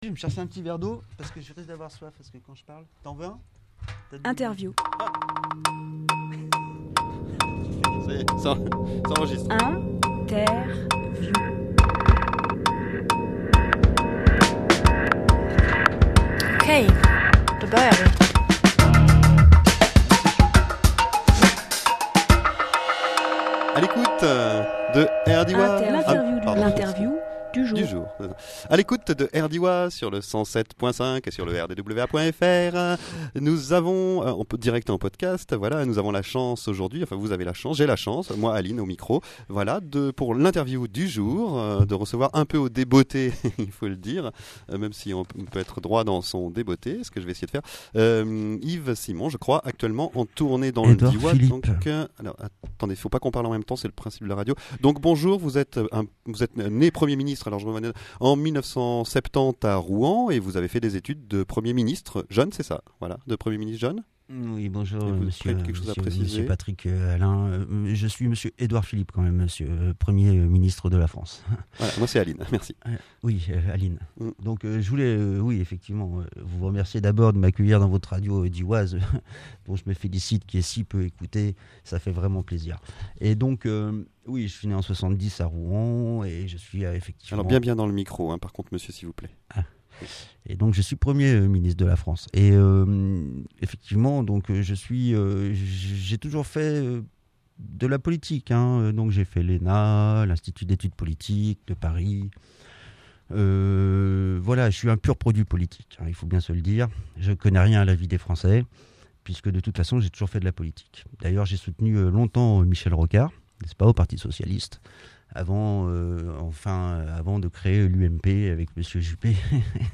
Vraie fausse Interview de Edouard Philippe - RDWA - Radio Diois
Lieu : Studio RDWA